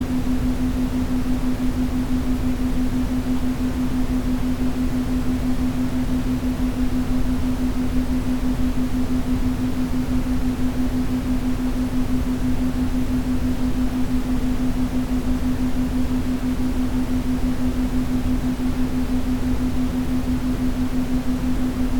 Brown noise (a sound that helps you fall asleep)
• Quality: High